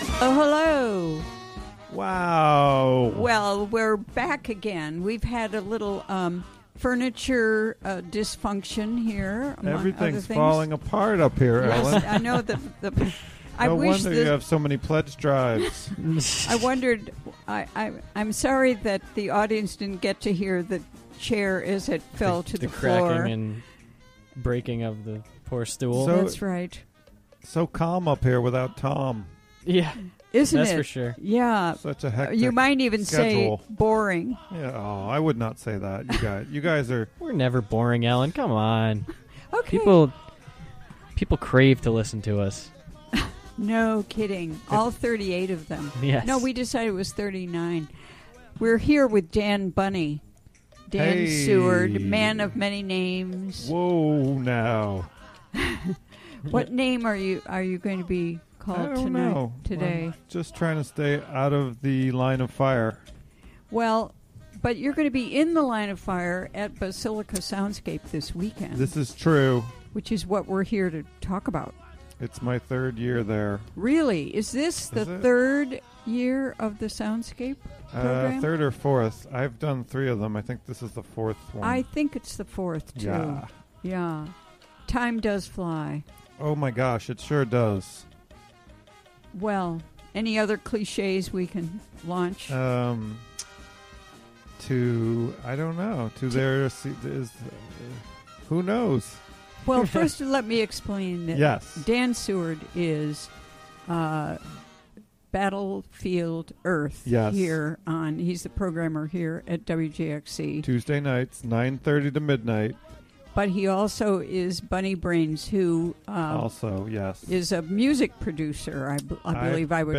Interview conducted during the WGXC Afternoon Show.